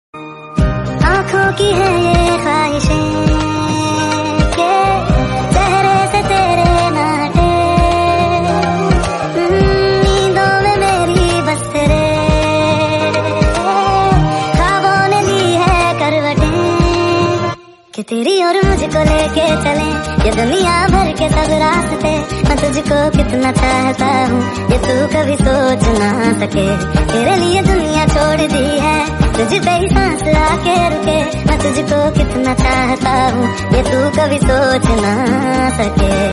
gune fowl sounds sound effects free download